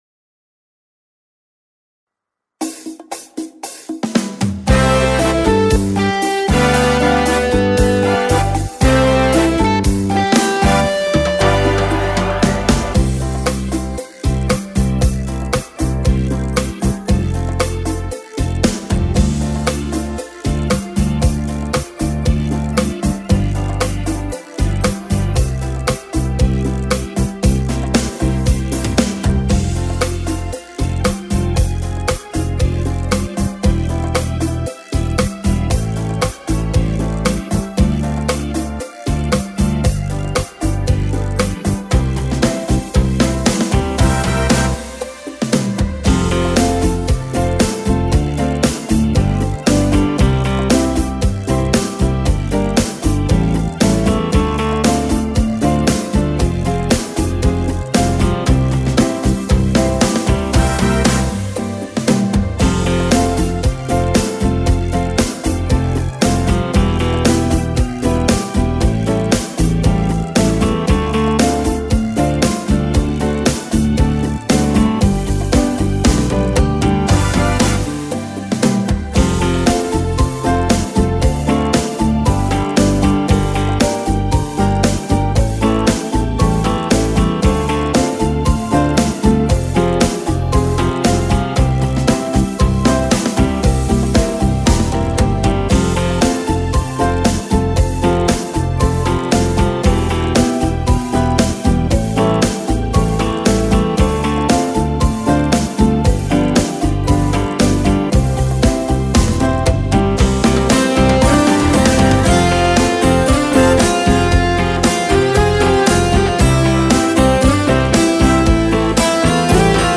Tone Nam (F#) - Nữ (C)